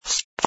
sfx_fturn_male04.wav